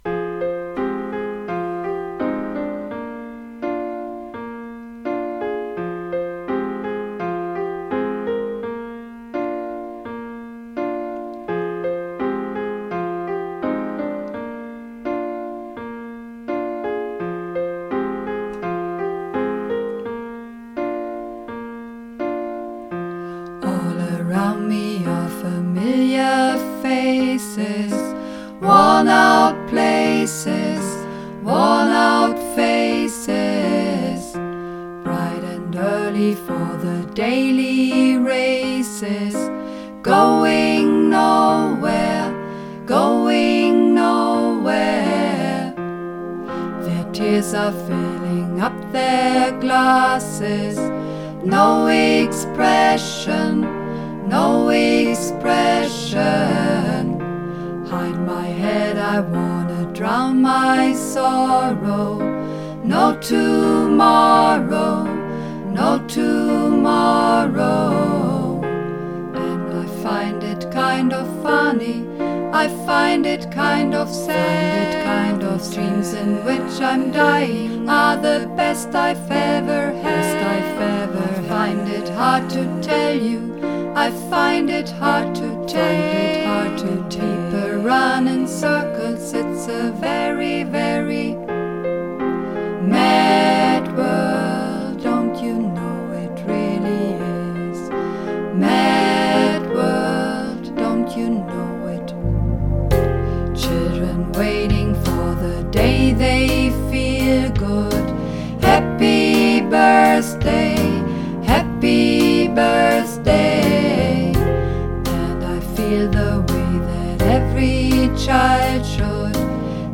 Runterladen (Mit rechter Maustaste anklicken, Menübefehl auswählen)   Mad World (Mehrstimmig)
Mad_World__4_Mehrstimmig.mp3